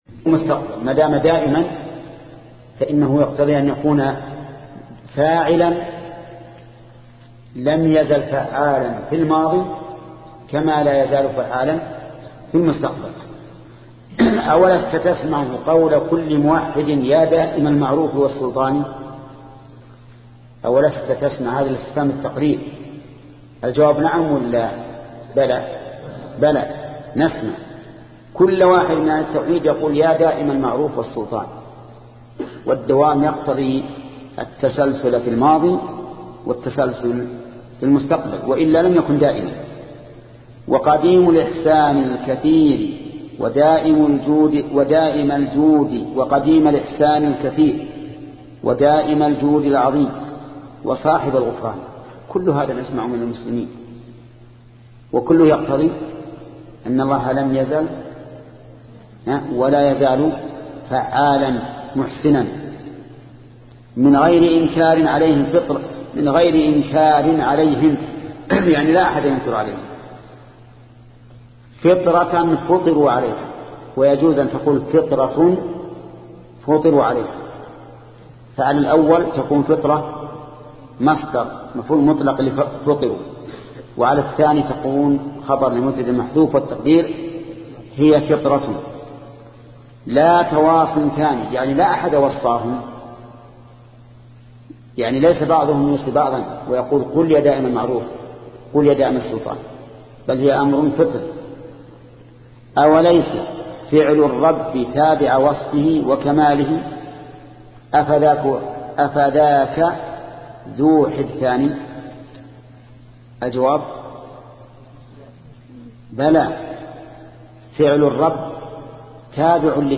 شبكة المعرفة الإسلامية | الدروس | التعليق على القصيدة النونية 11 |محمد بن صالح العثيمين